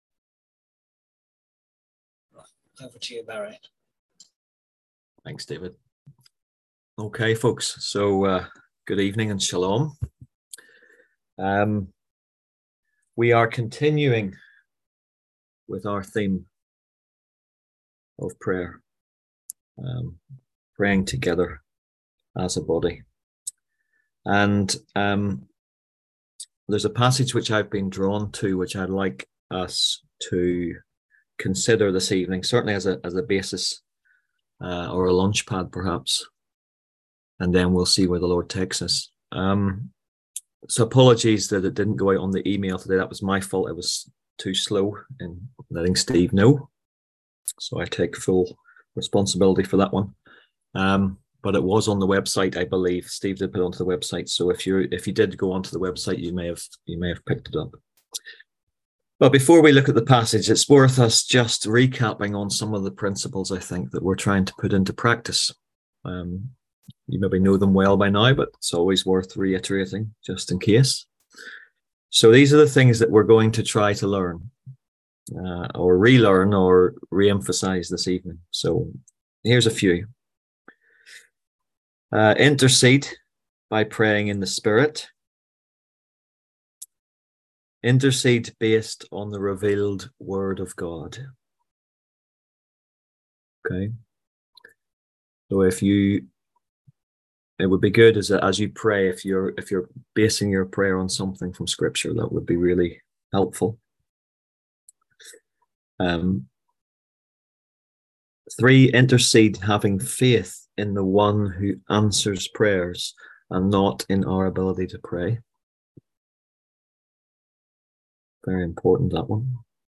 On August 7th at 7pm – 8:30pm on ZOOM ASK A QUESTION – Our lively discussion forum.